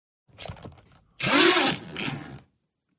Sci-Fi
Hologram Activate is a free sci-fi sound effect available for download in MP3 format.
416_hologram_activate.mp3